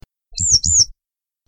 Bergeronnette printanière, motacilla flava